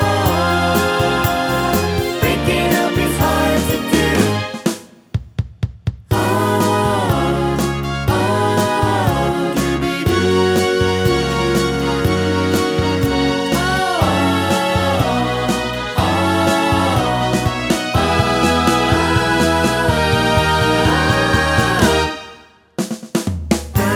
Pop (1970s) 3:11 Buy £1.50